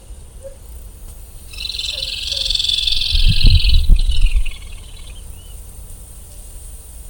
Rufous-sided Crake (Laterallus melanophaius)
Country: Brazil
Province / Department: Alagoas
Condition: Wild
Certainty: Observed, Recorded vocal